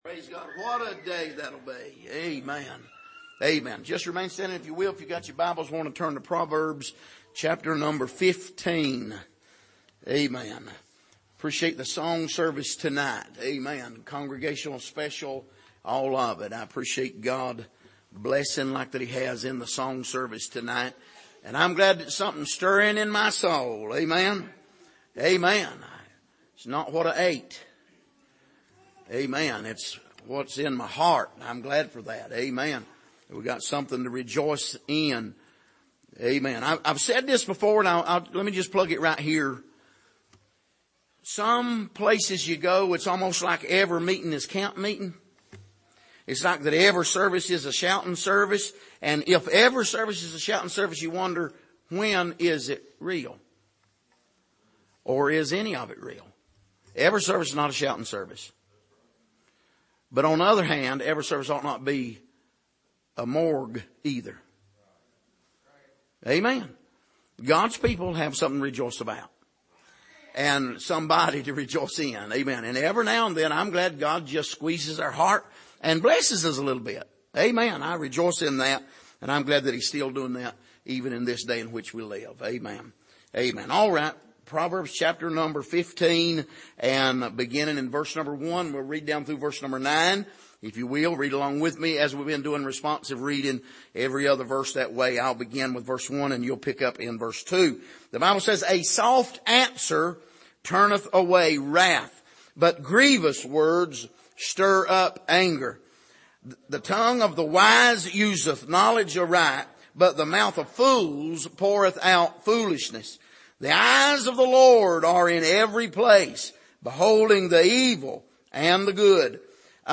Passage: Proverbs 15:1-9 Service: Sunday Evening